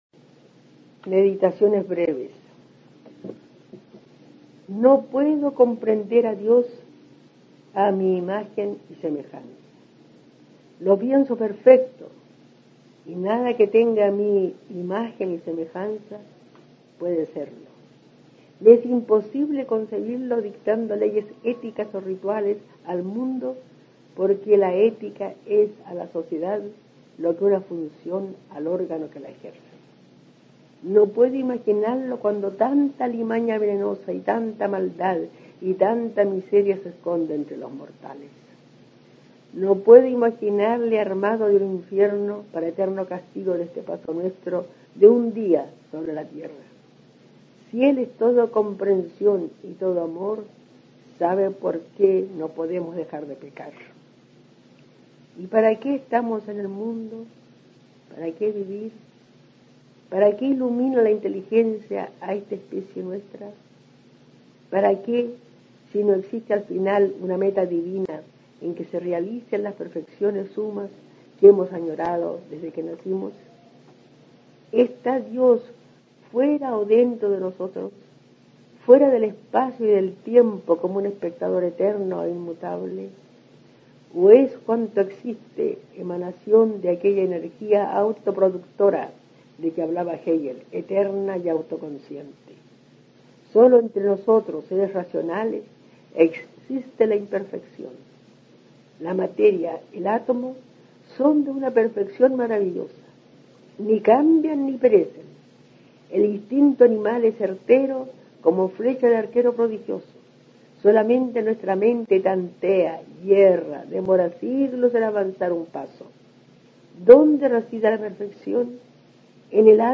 Aquí se puede escuchar a la destacada profesora chilena Amanda Labarca (1886-1975) dando cuenta de algunas de sus reflexiones e inquietudes. Las del presente texto giran en torno a Dios y la condición humana, planteando ciertas dudas que impone, a veces, la razón a la fe.